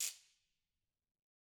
Ratchet1-Crank_v1_rr1_Sum.wav